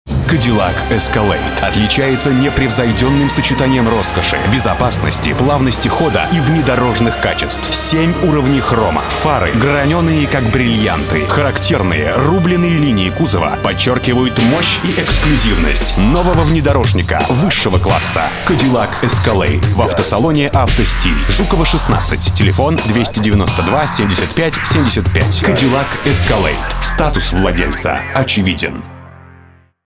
Cadilak Категория: Аудио/видео монтаж
Пафосно и сильно. Музыка на вступлении - идея заказчика.